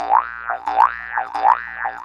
forcefield.wav